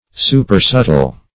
Supersubtle \Su`per*sub"tle\, a.